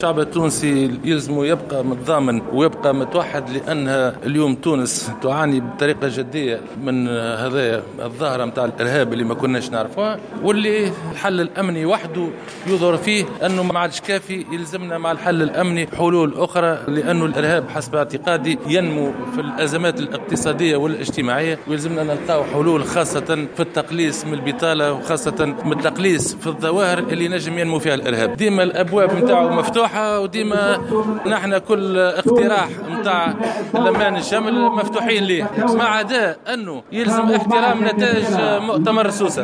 دعا القيادي بنداء تونس حافظ قايد السبسي اليوم الأحد أعضاء حزبه لاحترام نتائج مؤتمر سوسة للحزب في تصريحات على هامش احتفالية نظمها اليوم نداء تونس بمناسبة الذكرى 60 للاستقلال.